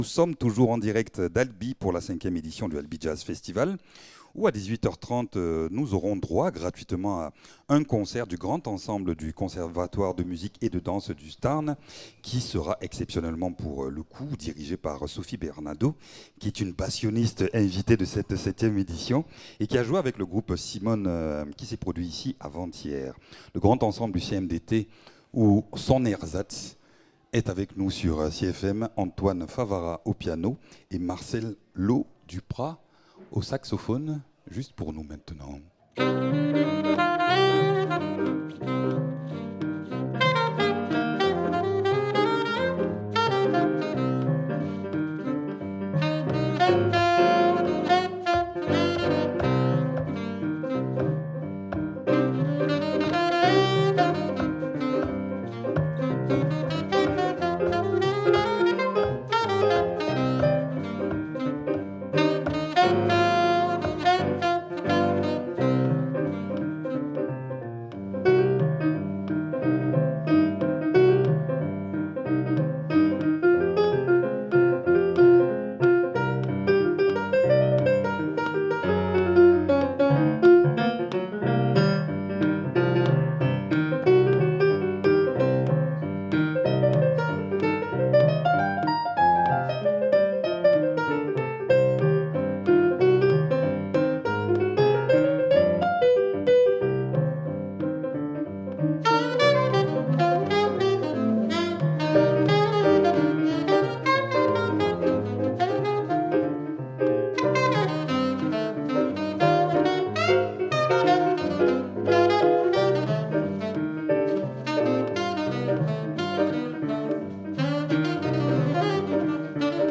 bassoniste